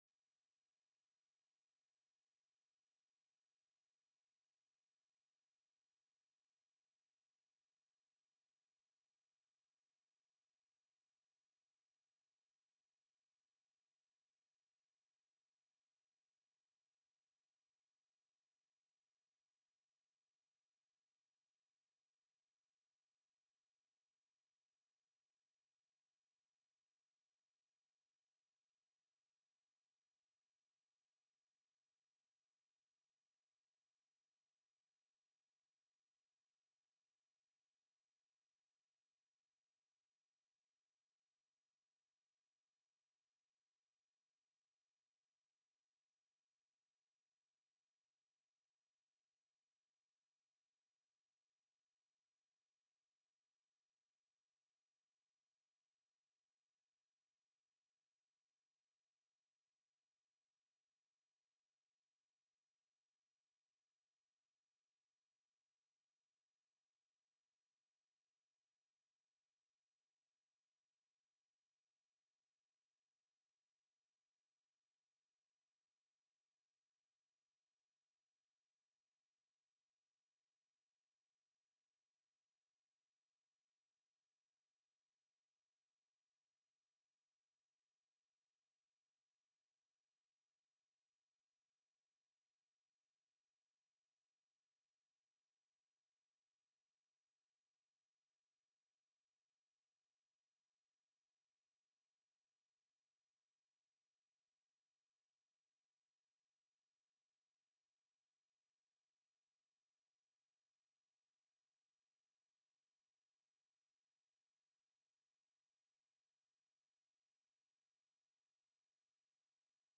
The audio recordings are captured by our records offices as the official record of the meeting and will have more accurate timestamps.
HB 17 DISABLED VETERANS: RETIREMENT BENEFITS TELECONFERENCED Heard & Held -- Invited & Public Testimony -- *+ HB 48 CIVIL LEGAL SERVICES FUND TELECONFERENCED Heard & Held -- Invited & Public Testimony --